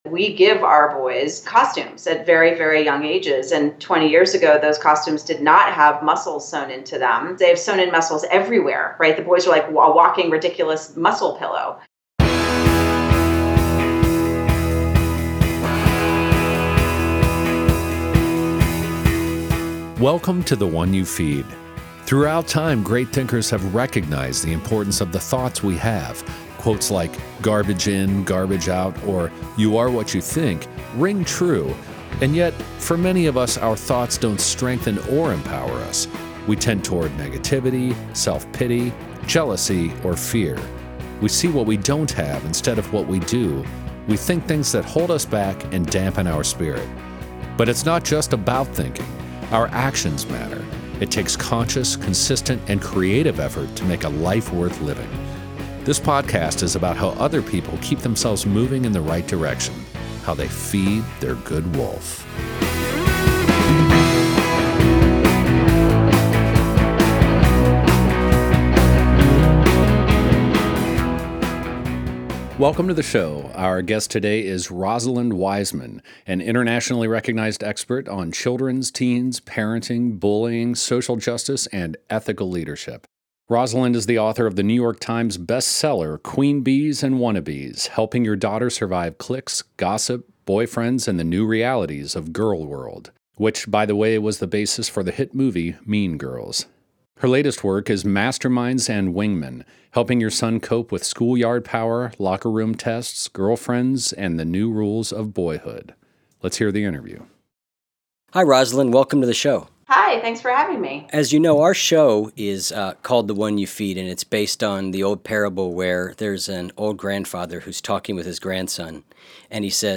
I heard this quote in one of my favorite podcasts The One you Feed. Here is the interview in its entirety...